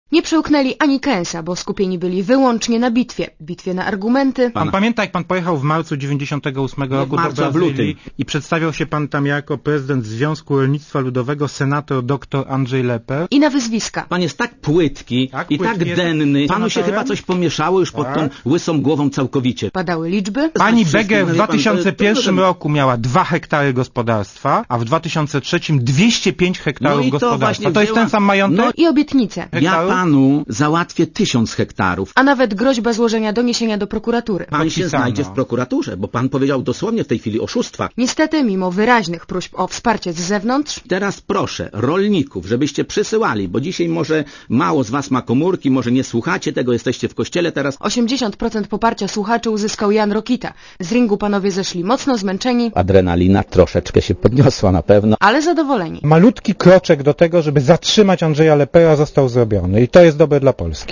Jan Rokita, Monika Olejnik i Andrzej Lepper (RadioZet)
Tak wyglądał scenariusz specjalnego wydania programu Siódmy Dzień Tygodnia w Radiu Zet. Naprzeciw siebie usiedli Jan Rokita z Platformy Obywatelskiej i lider Samoobrony Andrzej Lepper.